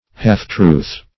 \half"-truth`\ (h[aum]f"tr[=oo]th)